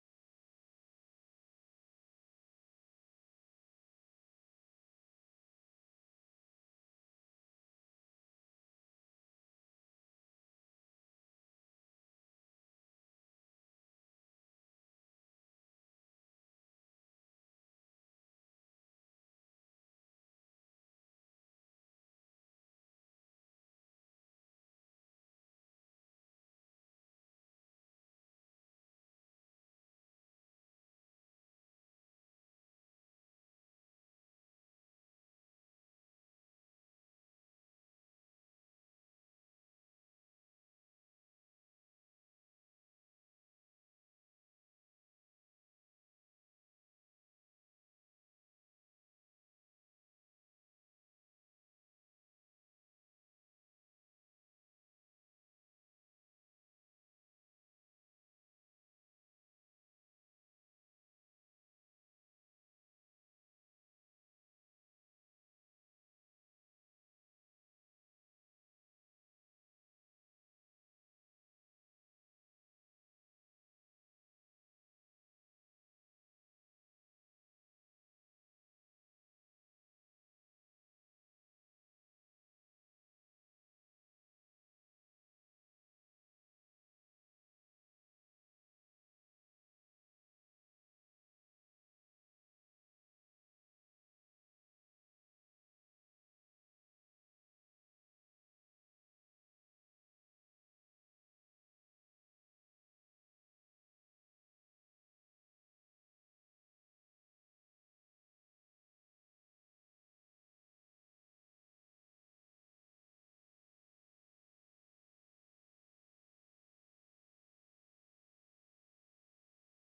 Agenda Nieuwegein - Avond van de Raad Digitaal vanuit zaal 008 donderdag 29 oktober 2020 20:00 - 22:20 - iBabs Publieksportaal
De vergadering wordt digitaal gehouden gezien de aangescherpte maatregelen.